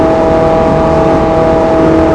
focussvt_low.wav